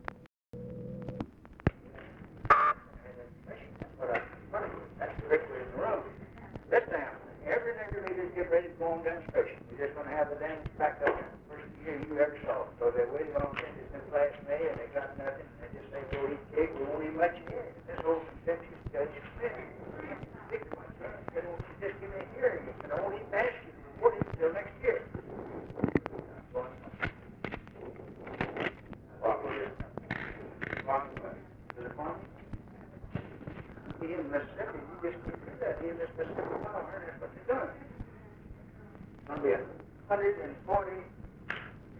OFFICE CONVERSATION, December 04, 1963
Secret White House Tapes